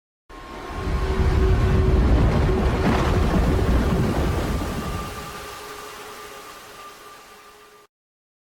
Шумы сигнализирующие о приближении Амбуш
Амбуш_шум_3.mp3.mp3